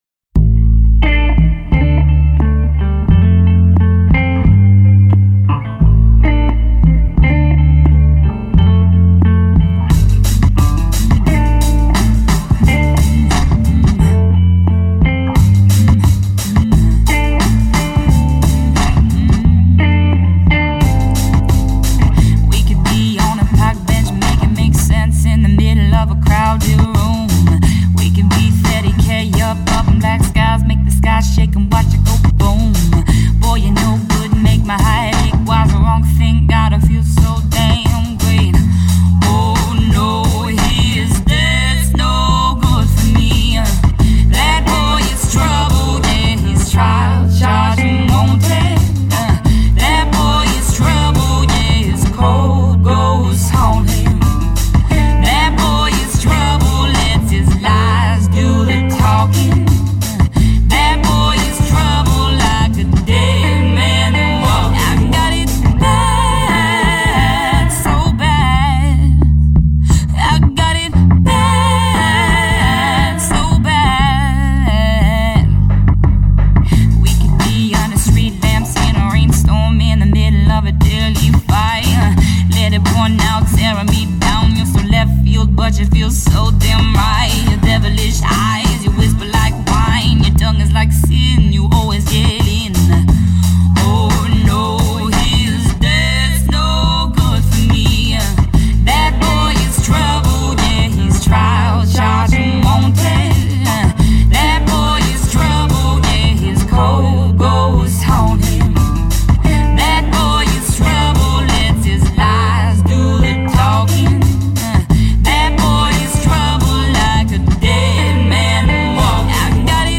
LA-based, Portland-bred songstress